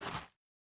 Bare Step Gravel Medium C.wav